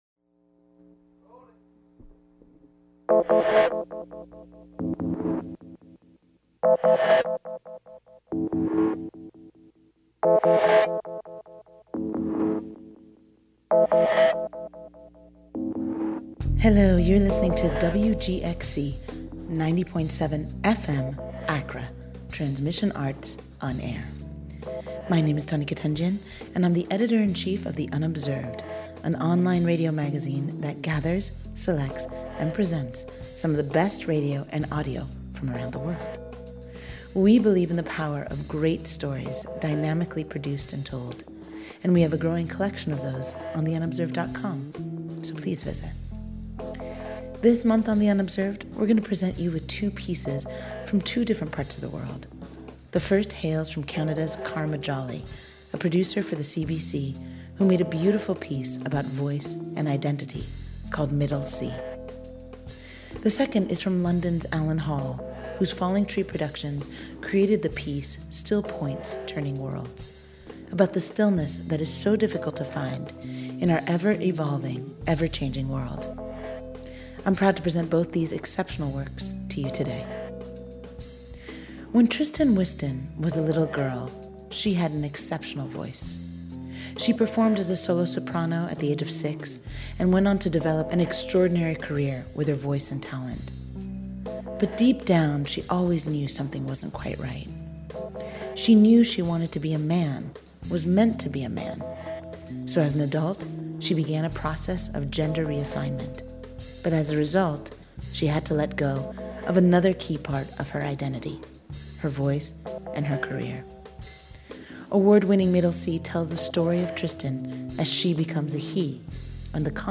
Transmission Arts & Experimental Sounds
The [Un]Observed is the online radio magazine that selects and presents work from the world's leading sound artists and radio producers.